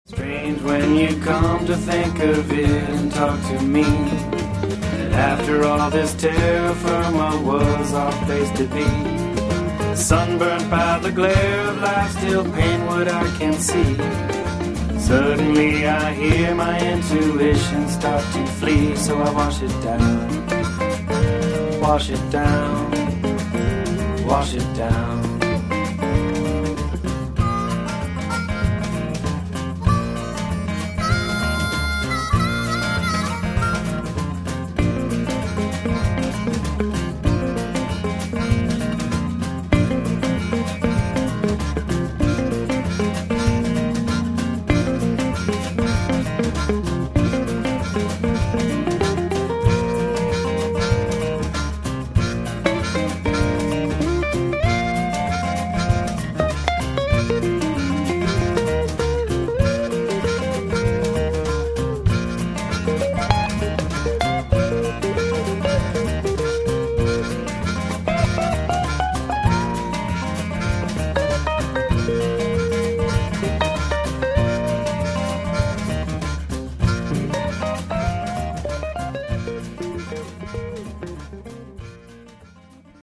(singer/songwriter), 2003